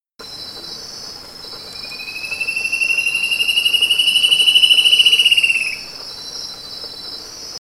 Spot-backed Antshrike (Hypoedaleus guttatus)
Misiones-junio-2018-1289-Batara-Goteado.mp3
Location or protected area: Reserva Privada y Ecolodge Surucuá
Condition: Wild
Certainty: Recorded vocal